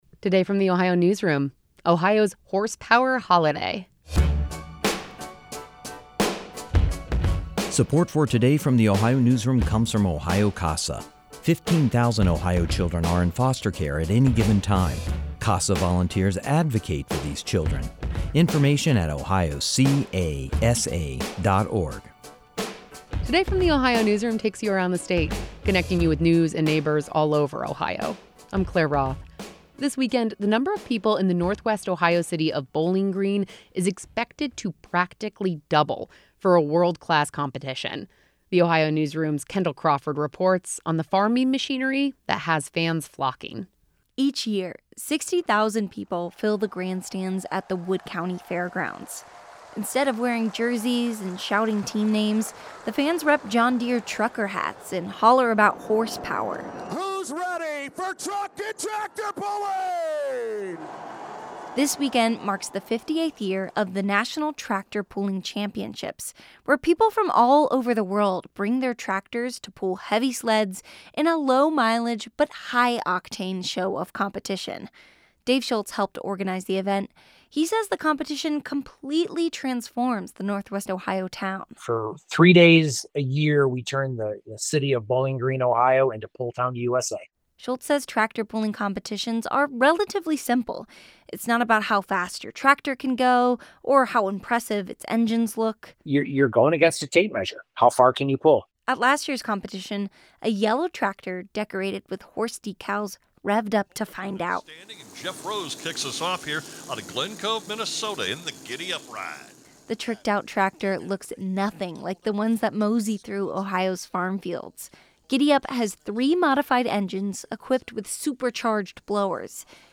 A John Deere tractor pulls a weighted sled at the National Tractor Pulling Championships in 2024.
"Who's ready for truck and tractor pulling?" an announcer bellowed into a microphone.